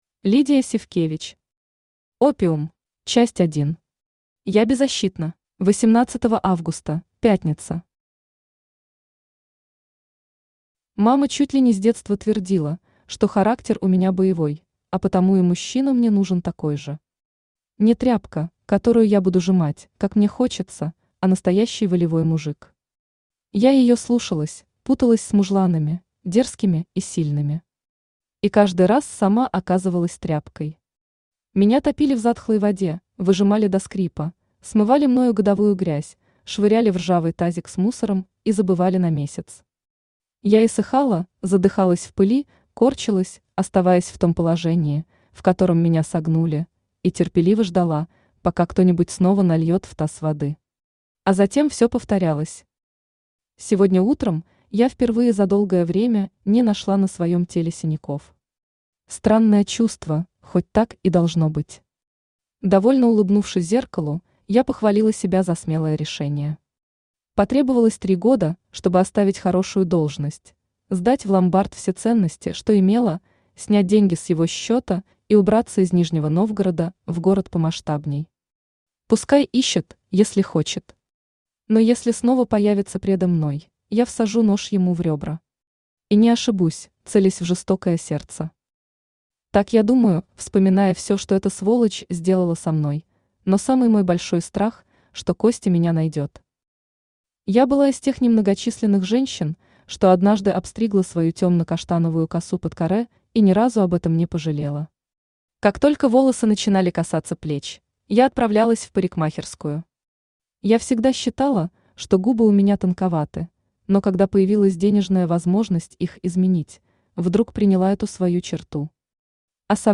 Aудиокнига Опиум Автор Лидия Сивкевич Читает аудиокнигу Авточтец ЛитРес.